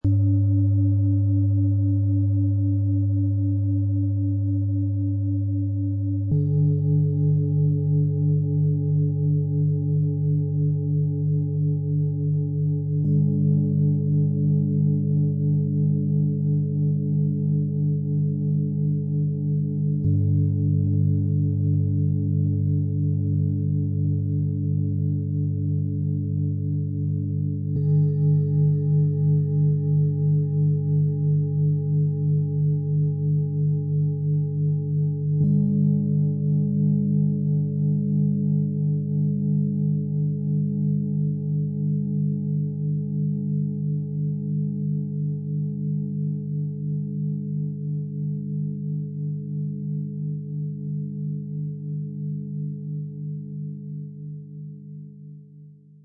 Sanfte Stabilität & feine Vibration - 3er Klangschalen-Massage-Set für sensible Menschen, auch für Jugendliche mit ADHS - Ø 16,7 - 21,9 cm, 2,55 kg
Die drei eher dünnwandigen Klangschalen erzeugen eine spürbare, aber nicht überwältigende Vibration, die sammelt, Halt gibt und Orientierung unterstützt.
Der Klang dieses Sets wirkt beruhigend, zentrierend und ausgleichend.
Ihr freundlicher, harmonischer Klang unterstützt Ruhe und Klarheit im Bauch- und Herzbereich.
Ihre Schwingung ist leichter und feiner, dabei stabil und ausgleichend - ideal für sensible Menschen und ruhige Abschlüsse.
Im Sound-Player - Jetzt reinhören können Sie sich den authentischen Klang genau dieser drei Klangschalen in Ruhe anhören.
So bekommen Sie ein gutes Gefühl für die ruhige, stabile und fein vibrierende Klangqualität dieses Sets.
Bengalen Schale, matt